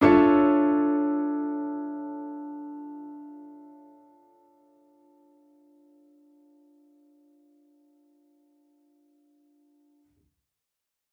Index of /musicradar/gangster-sting-samples/Chord Hits/Piano
GS_PiChrd-Dmin9maj7.wav